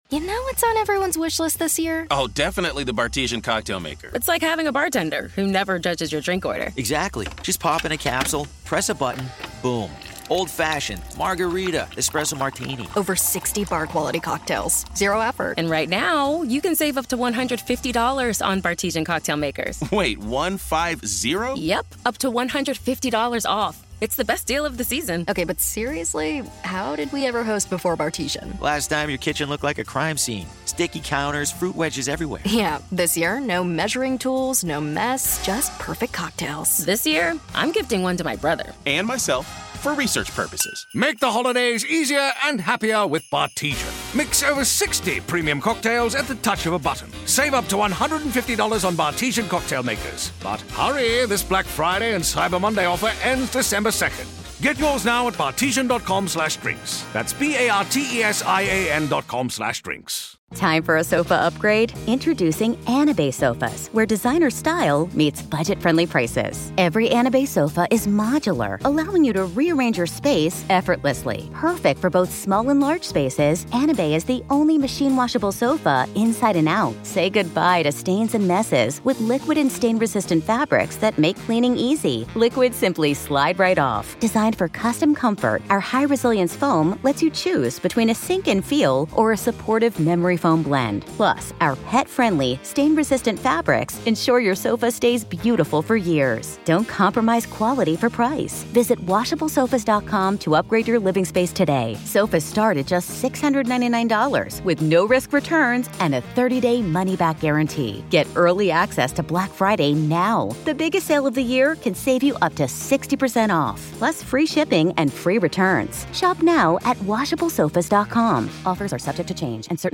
In this ongoing Hidden Killers courtroom-coverage series, we present the raw sounds of justice — no commentary, no edits — just the voices of attorneys, witnesses, and the judge as the case unfolds in real time.